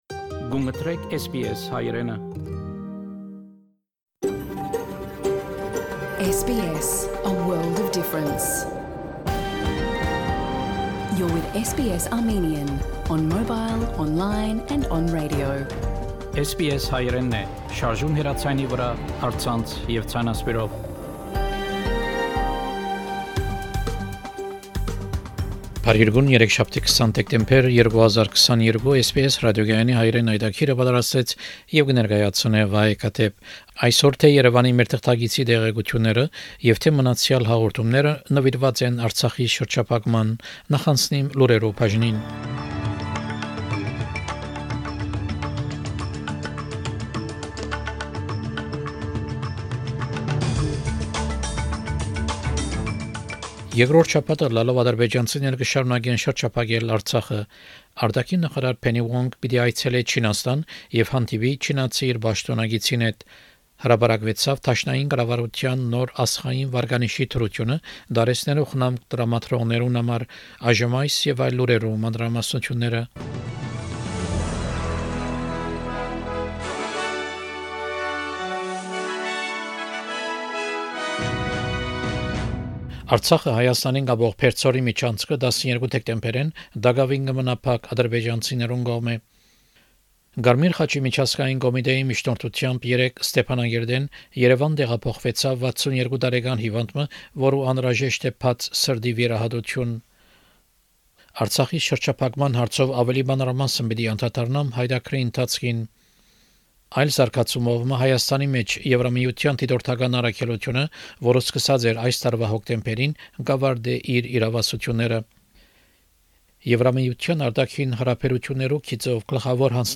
SBS Armenian news bulletin – 20 December 2022